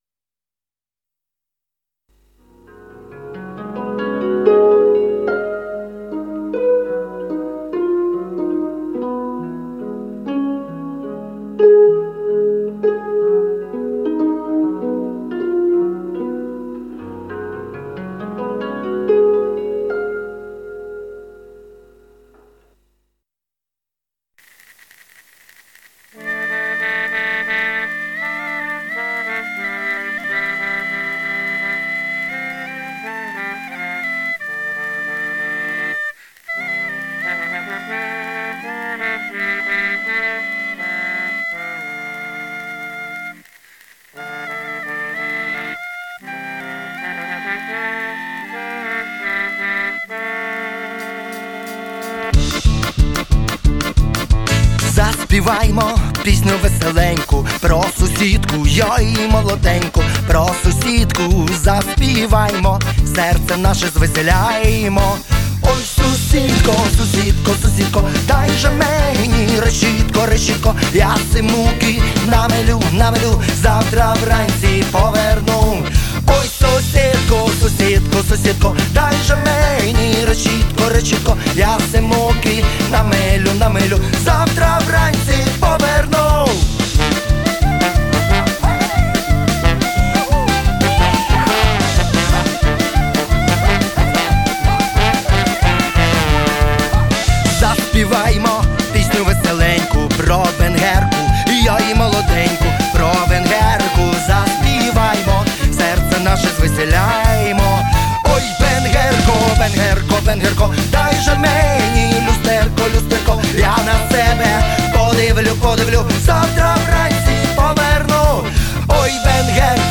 accordeon en zang